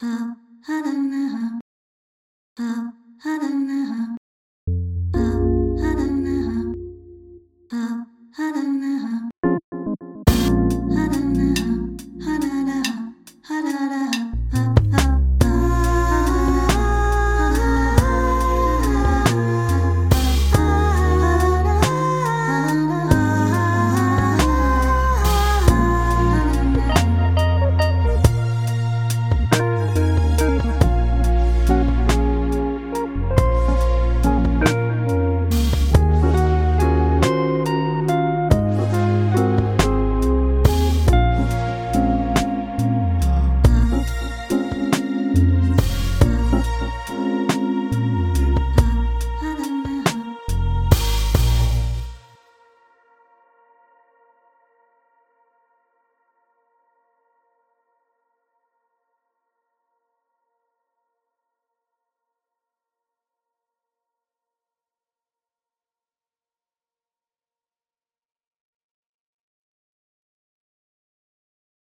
from my phone into Ableton Move and put stuff on top of it.